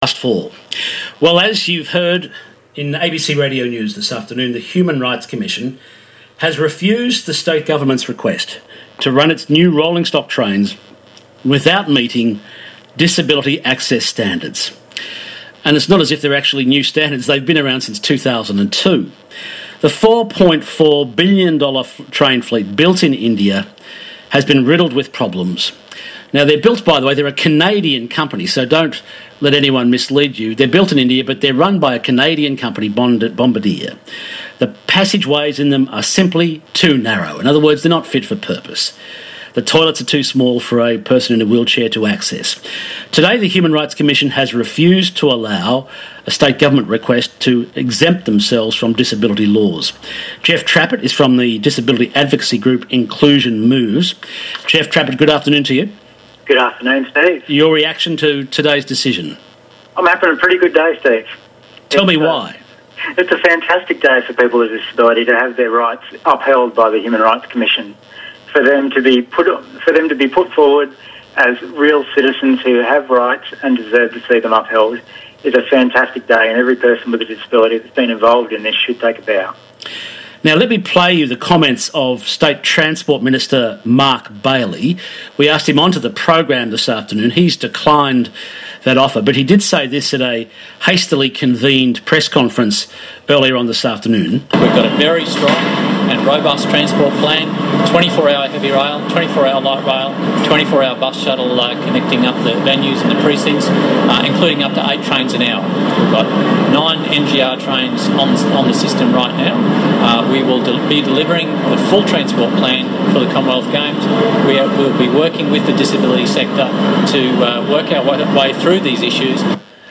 Interviews on ABC Radio Brisbane Drive